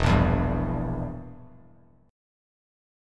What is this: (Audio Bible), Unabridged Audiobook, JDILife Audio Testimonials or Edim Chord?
Edim Chord